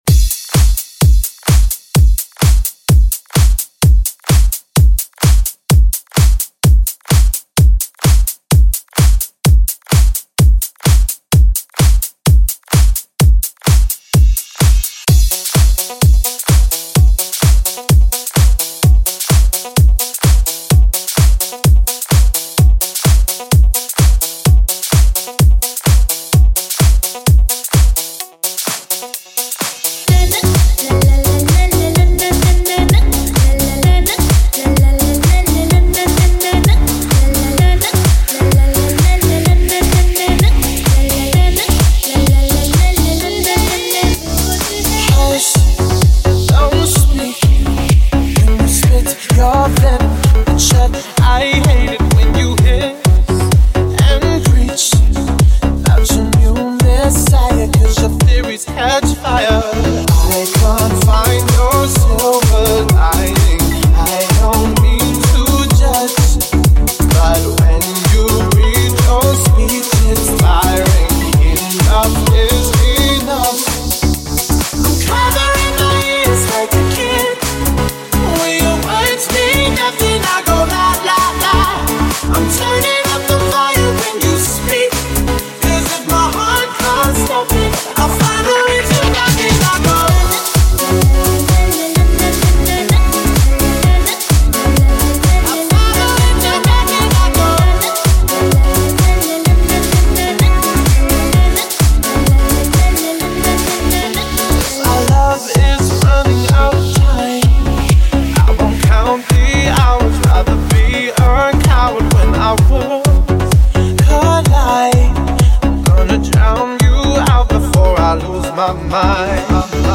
DANCE_HIT_MIX_2014.mp3